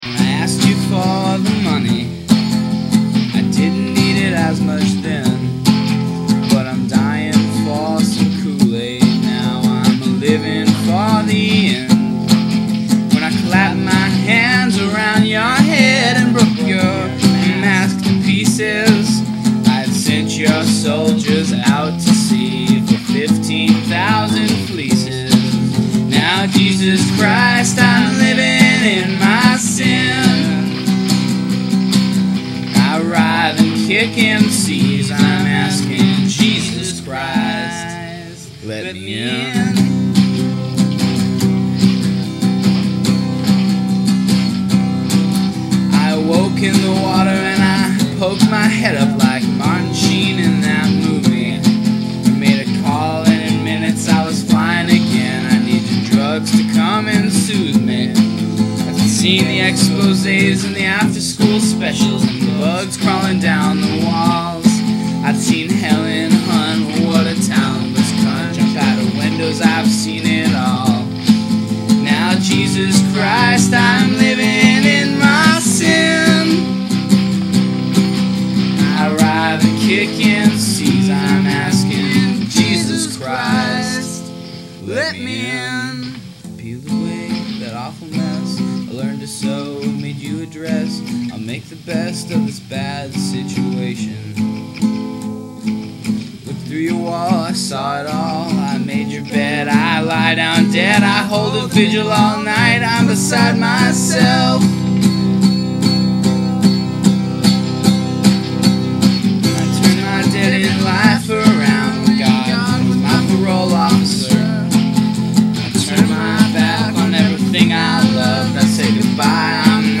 Uncommon Percussion